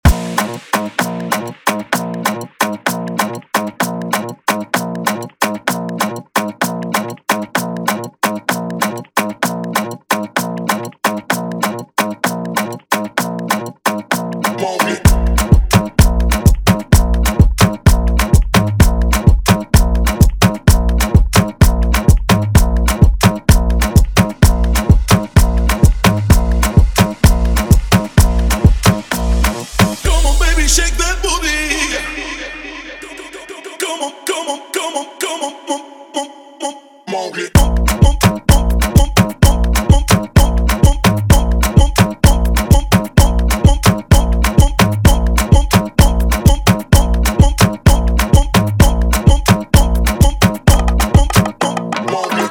guaracha, salsa remix, cumbia remix, EDM latino